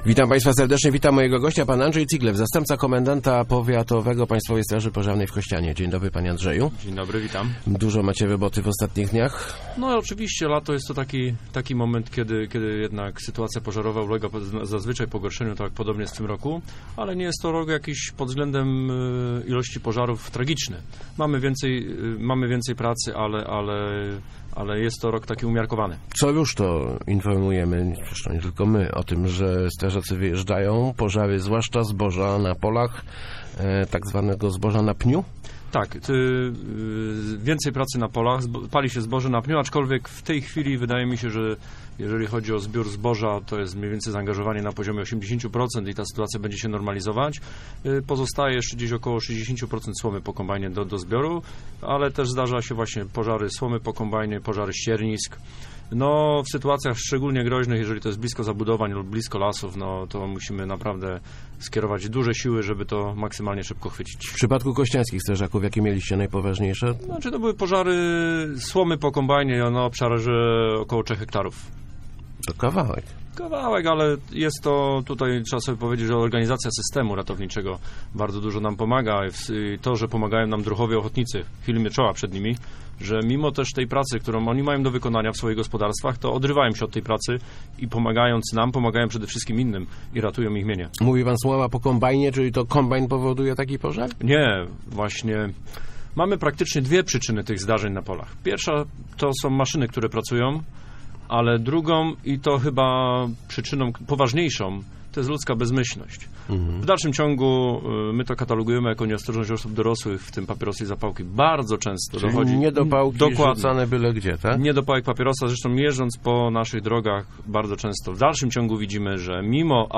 06.08.2015. Radio Elka